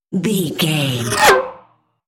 Sci fi vehicle pass by fast
Sound Effects
futuristic
pass by